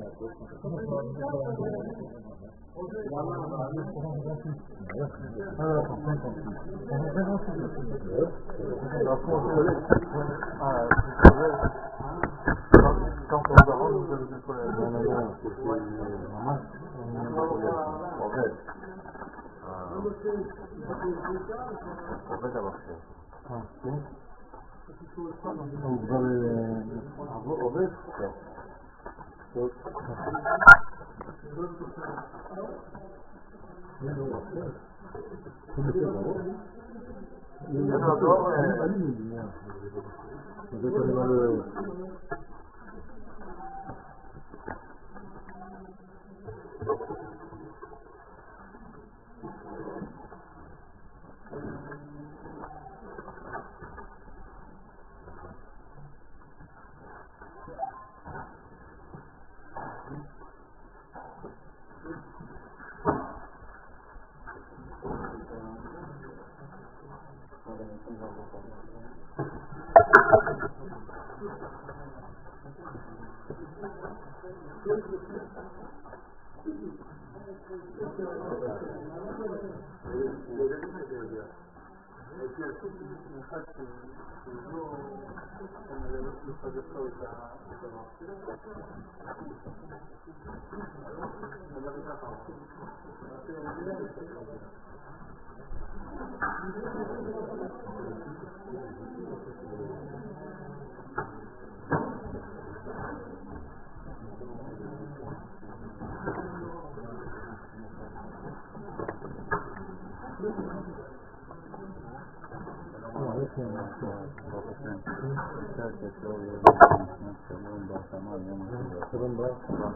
שיעורים, הרצאות